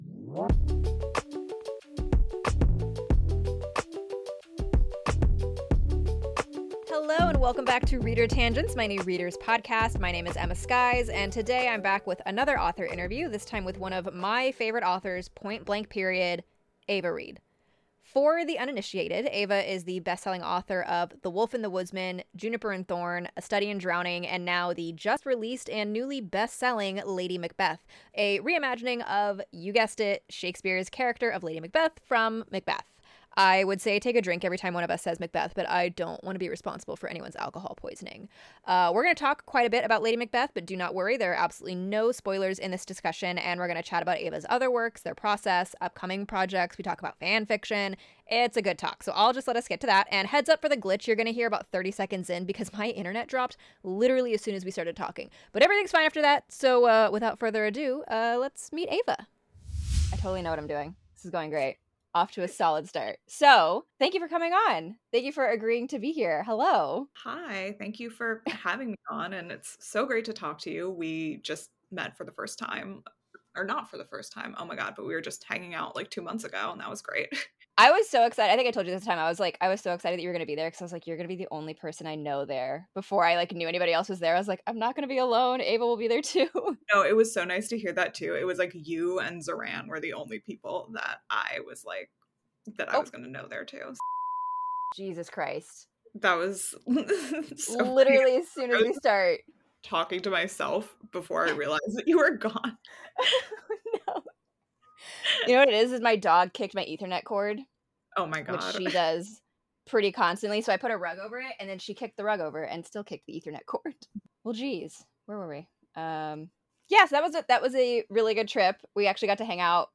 Author Interview: Ava Reid